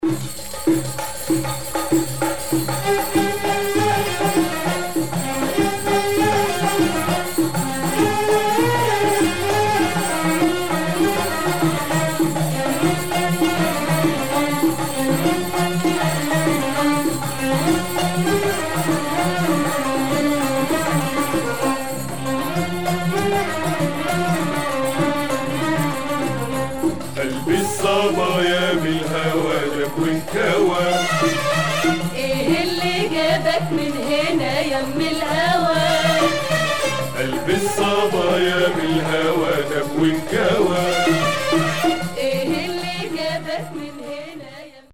Classic Egyptian sound with female and male vocals.